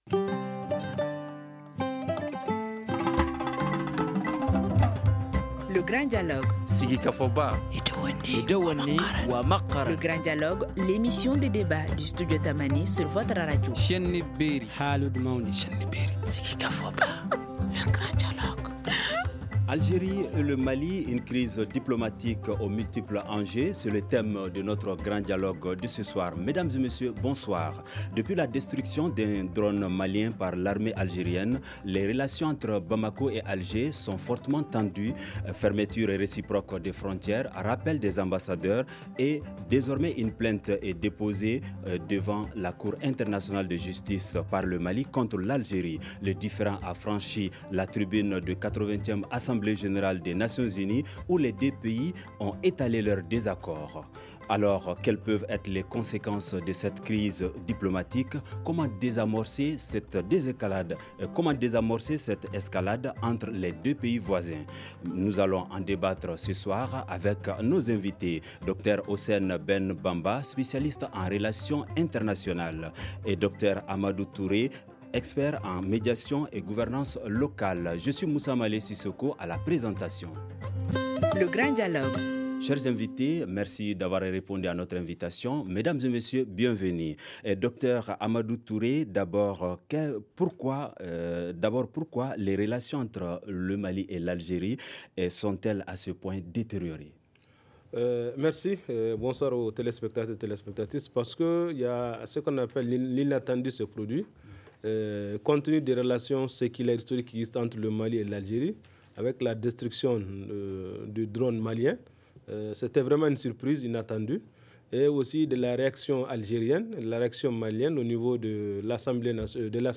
Nous allons en débattre ce soir avec :
spécialiste en relations internationales
expert en médiation et gouvernance locale